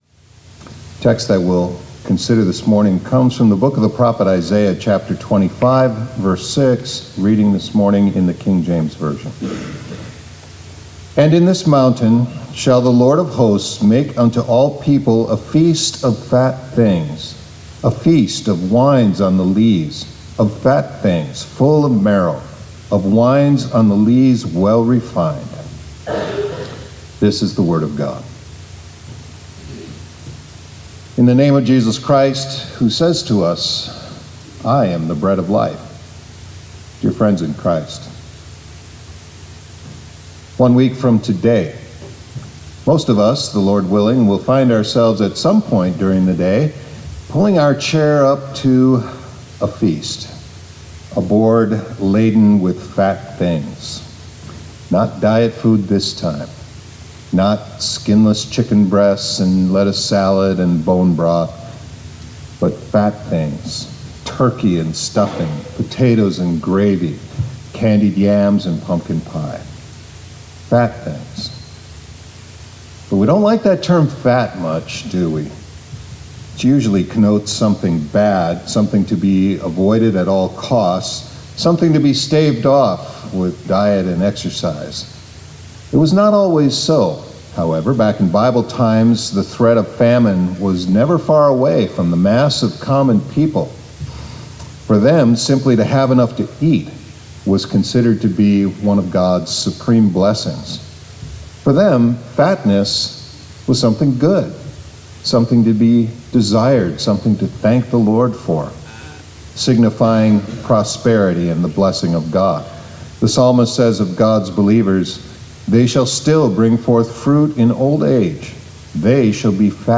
2024 Chapels -